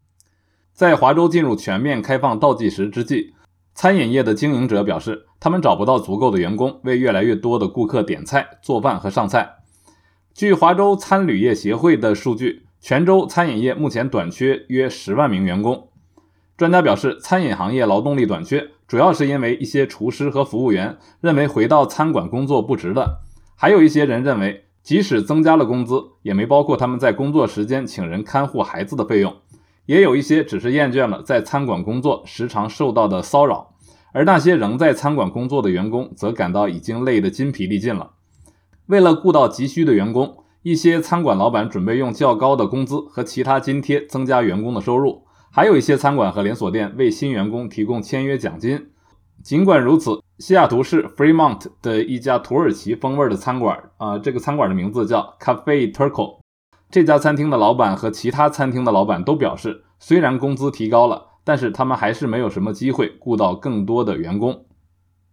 每日新聞